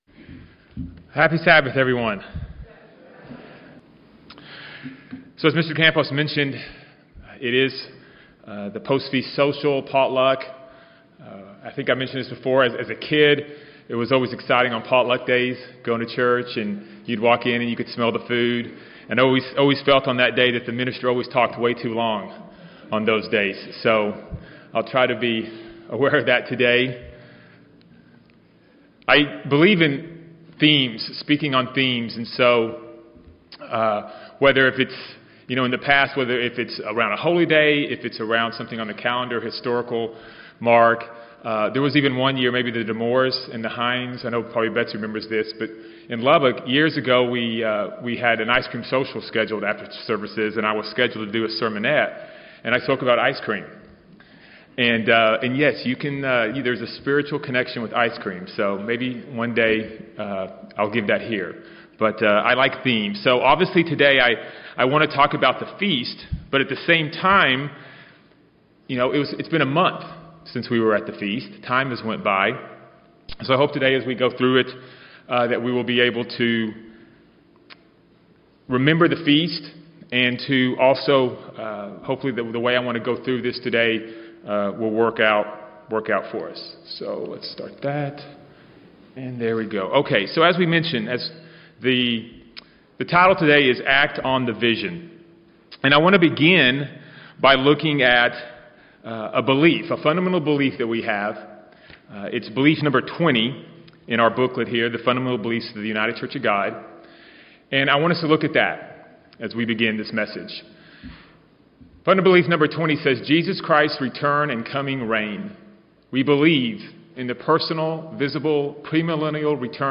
The vision that He gave us must produce change in our lives. In this message, we will discuss how God works with us and look at two individuals in the Bible that changed everything about their lives once God showed them a vision.
Given in Dallas, TX